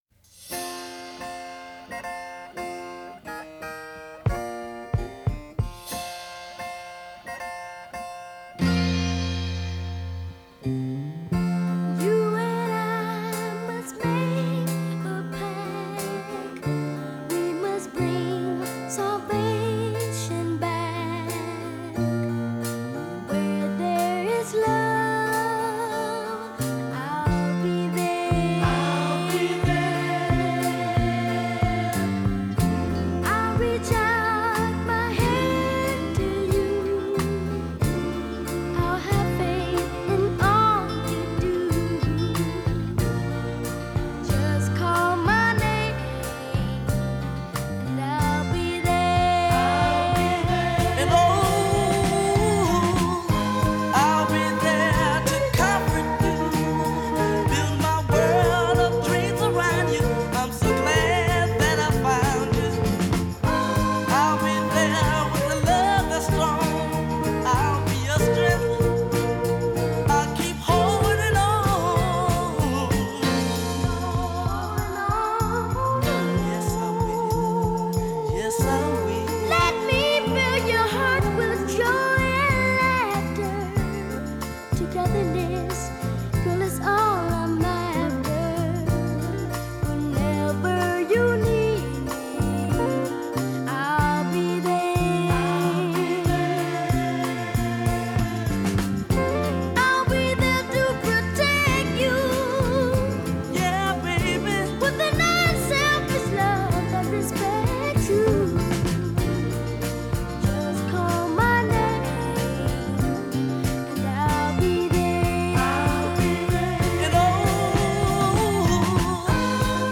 Transferred from a 24/192 high-res download.